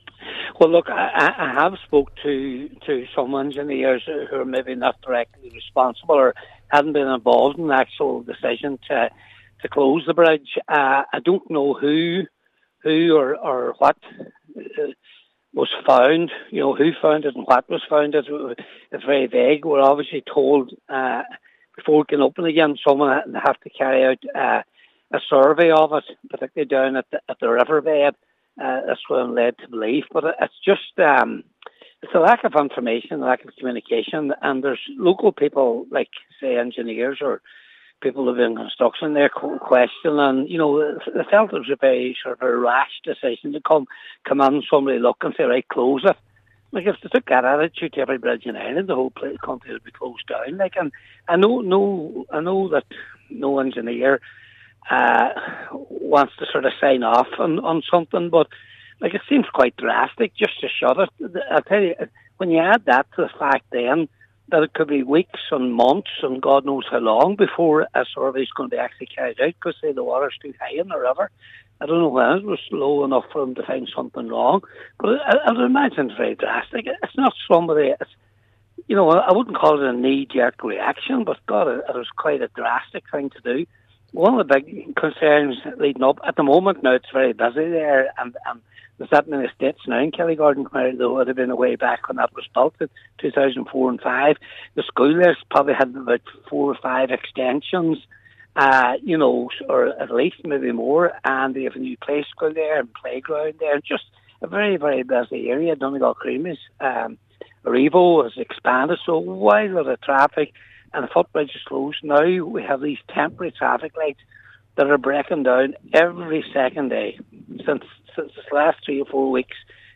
Cllr McGowan said the issue must be resolved before major traffic disruption begins in the Twin Towns during a planned six-month roadworks programme: